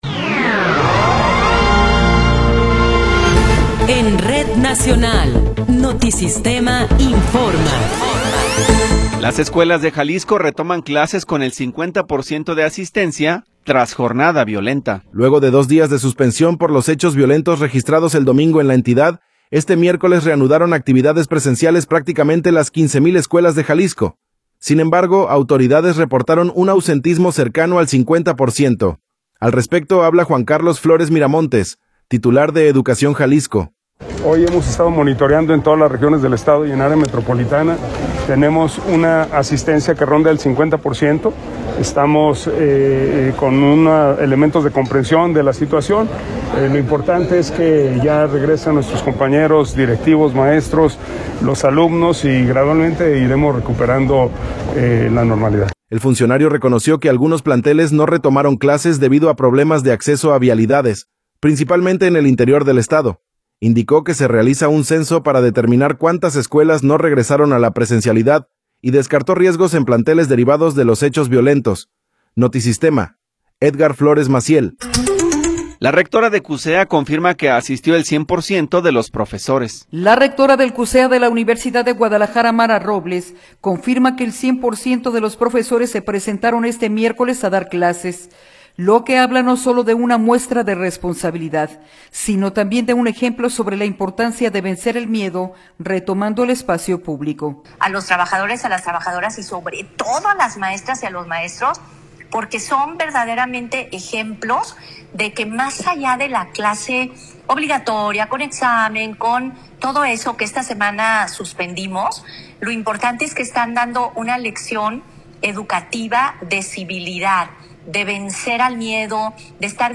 Noticiero 13 hrs. – 25 de Febrero de 2026